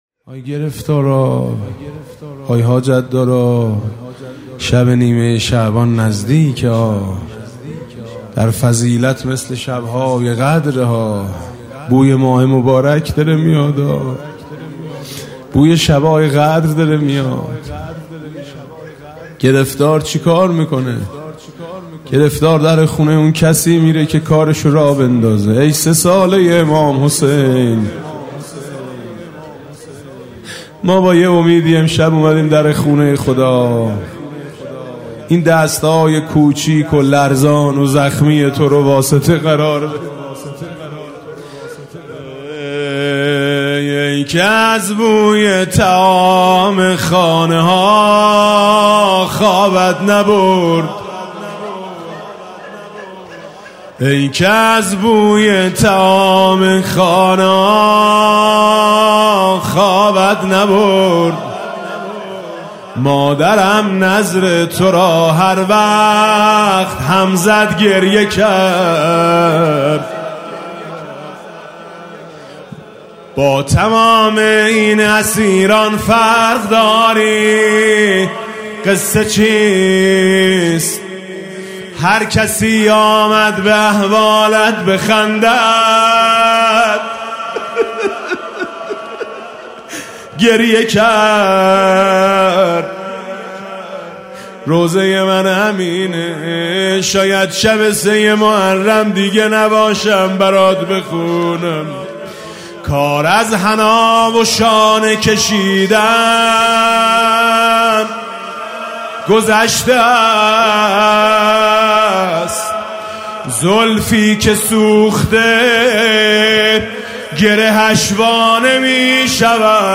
[آستان مقدس امامزاده قاضي الصابر (ع)]
با نوای: حاج میثم مطیعی